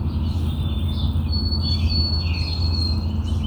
A recent observation using BirdNET — a free app that lets you record, ID, save and map observations so that you can learn birds and their songs.